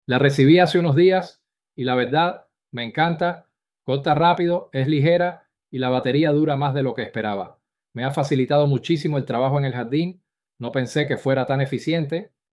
Opiniones de audio
Escuche lo que dicen nuestros clientes después de recibir el producto.